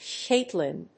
/ˈketlɪn(米国英語), ˈkeɪtlɪn(英国英語)/